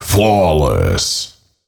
Фразы после убийства противника